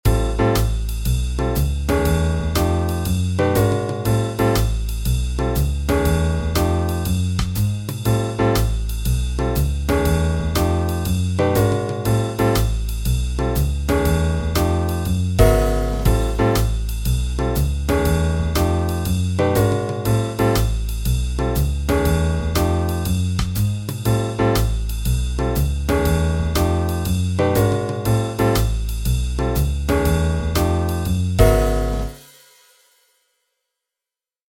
Demo of 11edo
11edo_groove.mp3